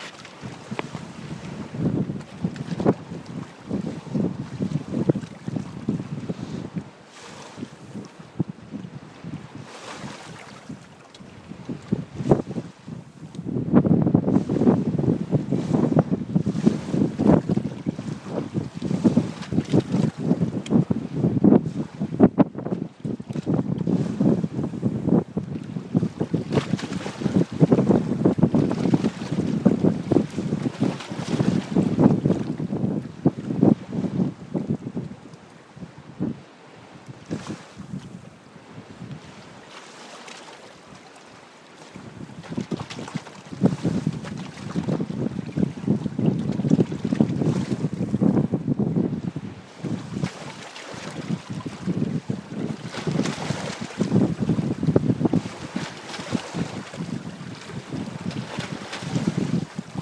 Hafengischt